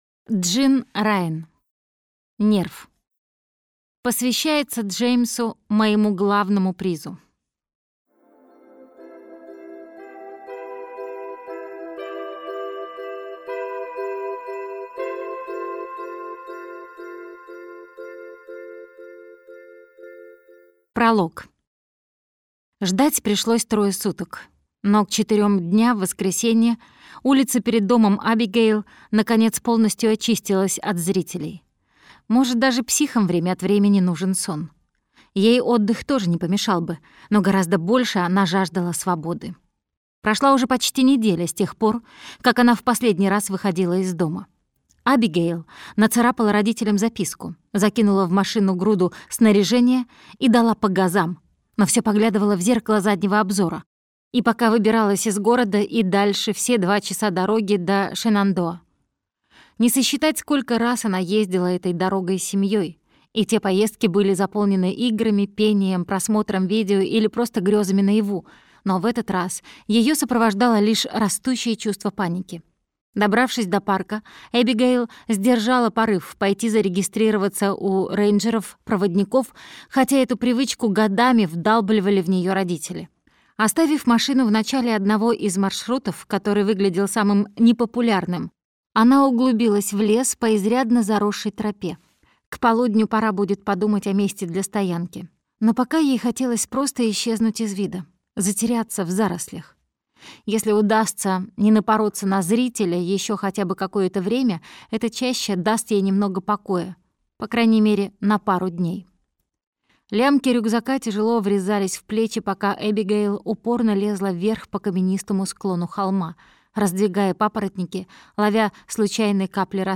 Аудиокнига Нерв | Библиотека аудиокниг